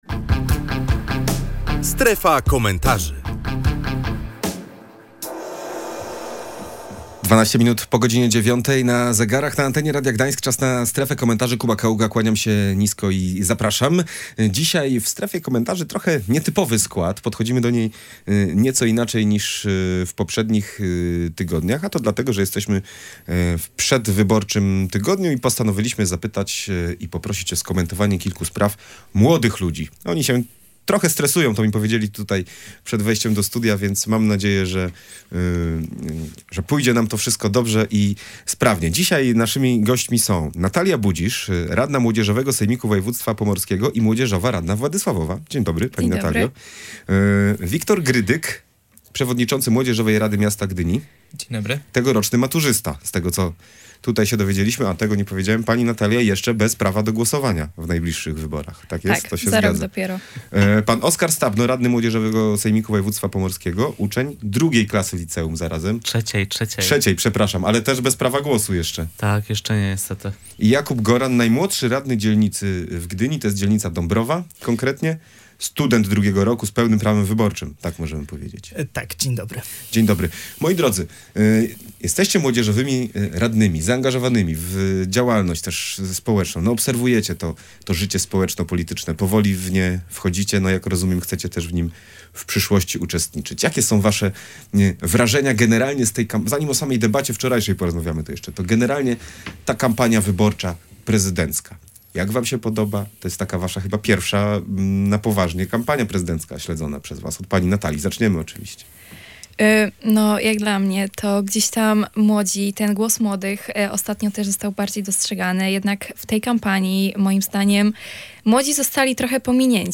W audycji rozmawialiśmy o tym, jak wygląda kampania prezydencka w oczach młodych ludzi, którzy powoli wchodzą w społeczno-polityczne życie.